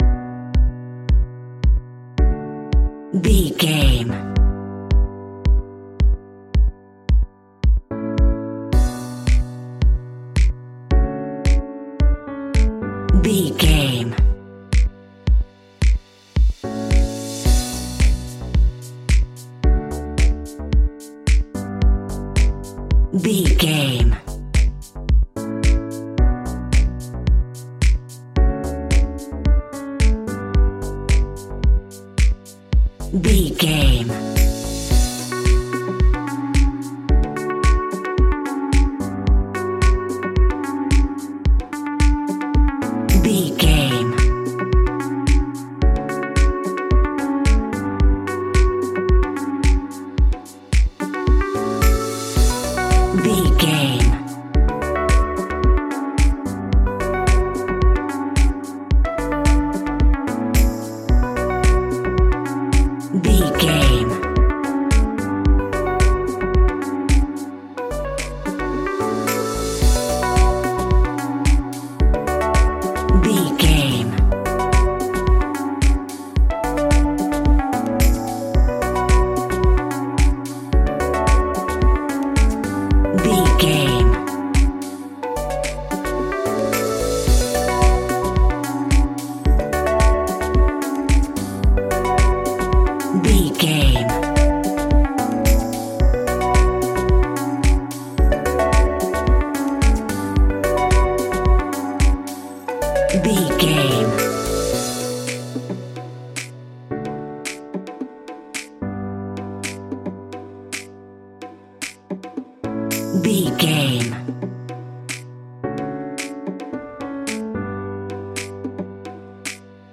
Aeolian/Minor
groovy
dreamy
peaceful
smooth
futuristic
drum machine
synthesiser
house
electro house
synth pop
funky house
synth leads
synth bass